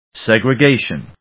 音節seg・re・ga・tion 発音記号・読み方
/sègrɪgéɪʃən(米国英語), ˌsegrʌˈgeɪʃʌn(英国英語)/